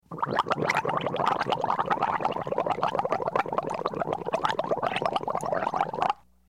Звуки полоскания горла
Пенится рот